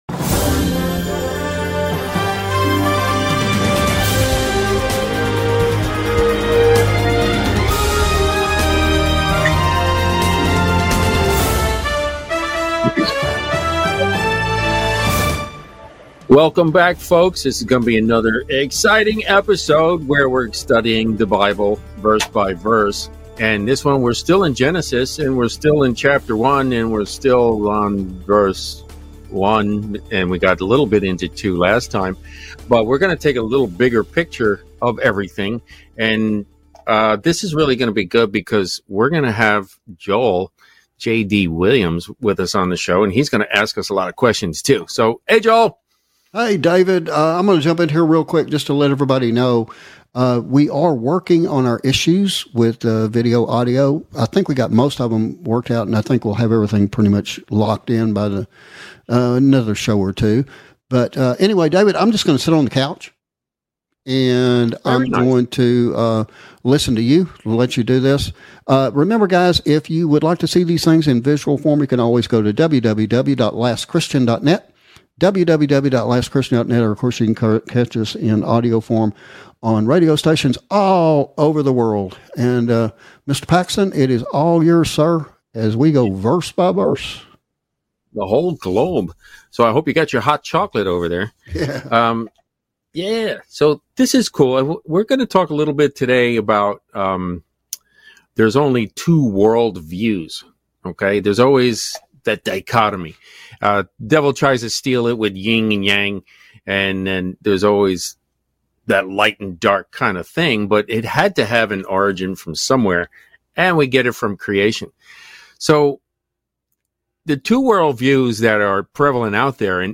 Weekly Comprehensive Verse by Verse Bible Study